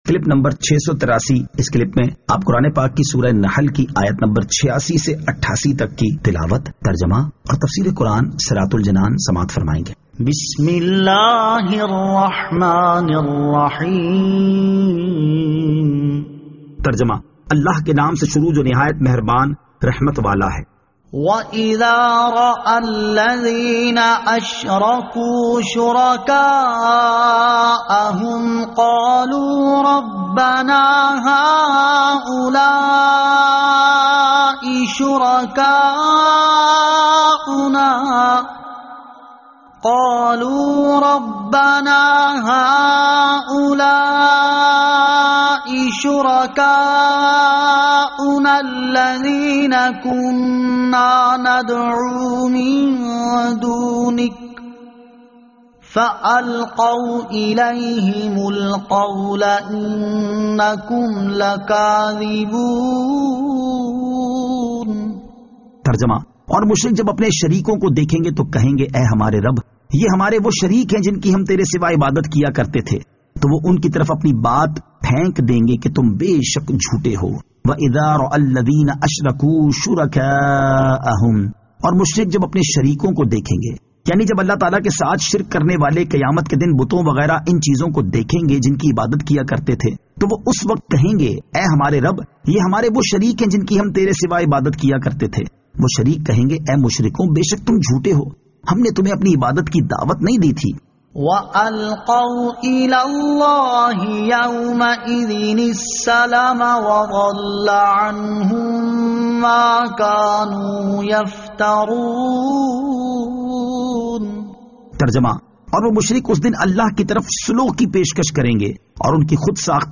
Surah An-Nahl Ayat 86 To 88 Tilawat , Tarjama , Tafseer
2021 MP3 MP4 MP4 Share سُوَّرۃُ النَّحٗل 86 تا 88 تلاوت ، ترجمہ ، تفسیر ۔